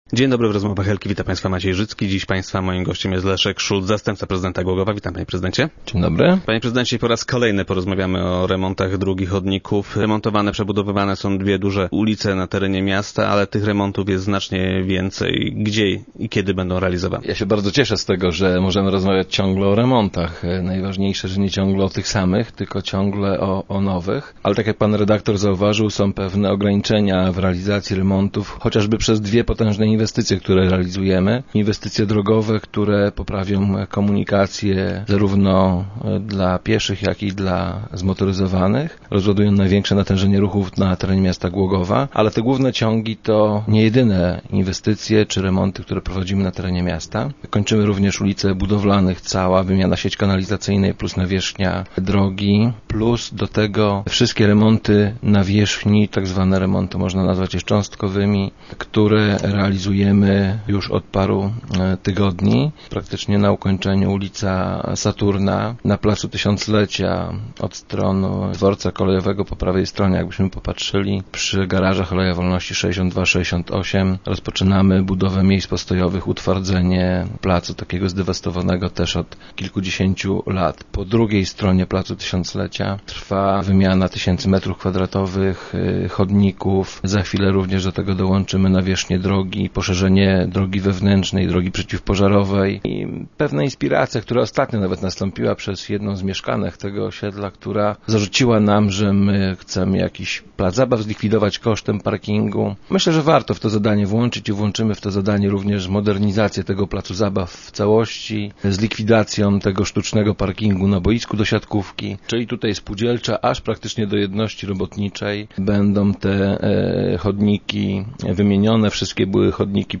Jak zapowiedział Leszek Szulc, zastępca prezydenta Głogowa i gość dzisiejszych Rozmów Elki, w tym roku wspólnie zadbają też o tereny zielone.